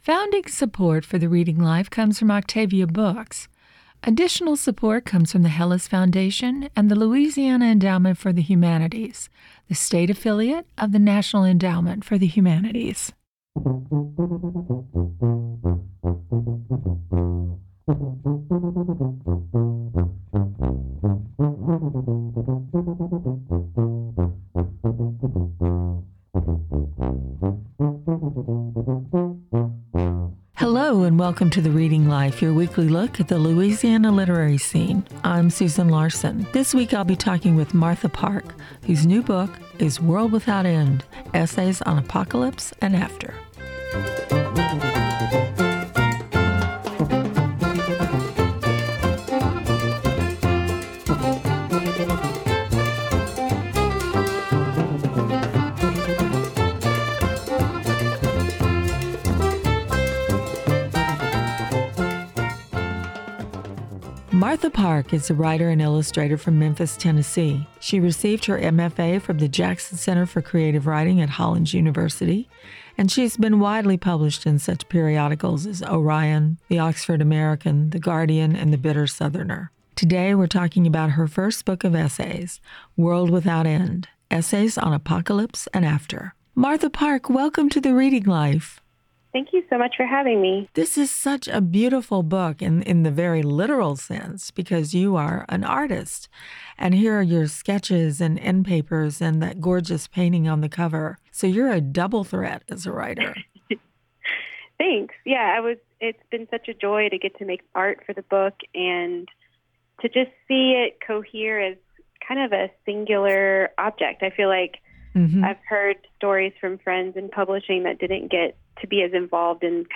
Hear celebrated and up-and-coming authors read excerpts from new books and discuss their work